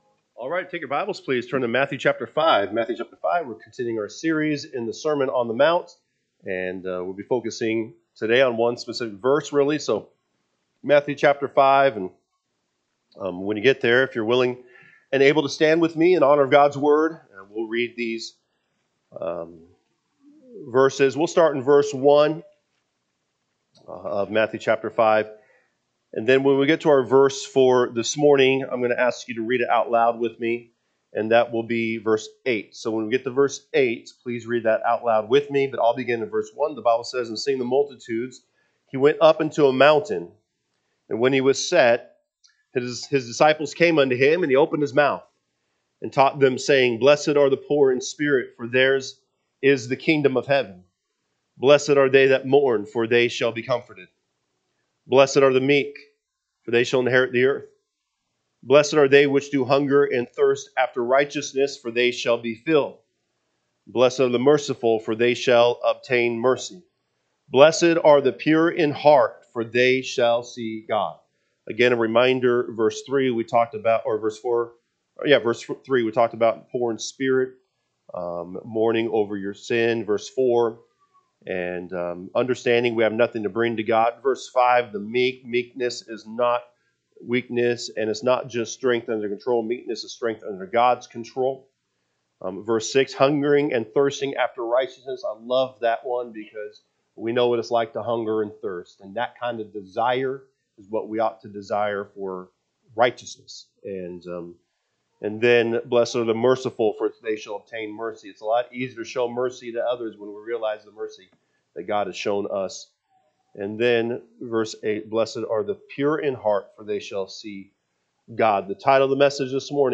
March 1, 2026 am Service Matthew 5:1-8 (KJB) 5 And seeing the multitudes, he went up into a mountain: and when he was set, his disciples came unto him: 2 And he opened his mouth, and taug…